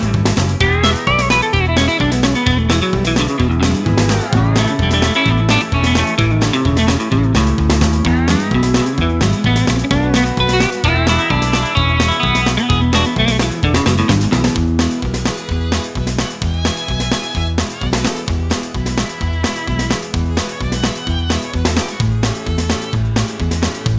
no Backing Vocals Country (Male) 3:01 Buy £1.50